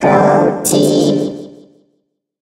evil_rick_start_vo_05.ogg